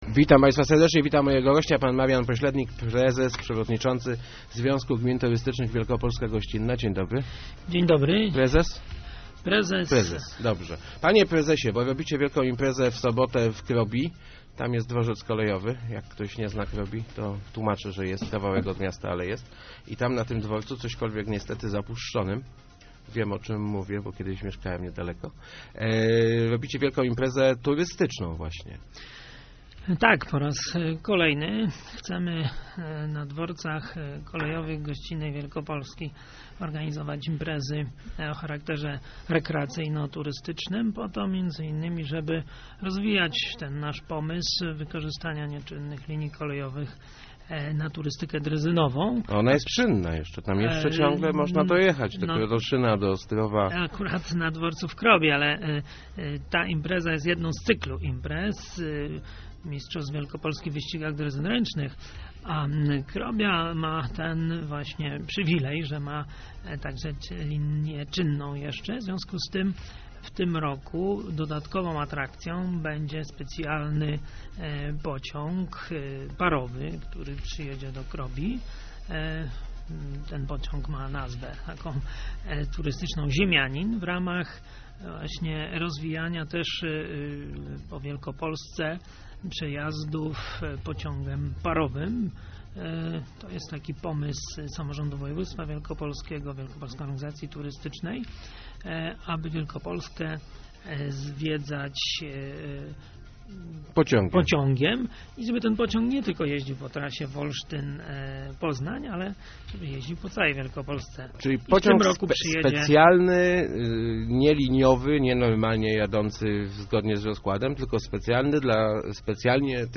Nudna rozmowa. 2.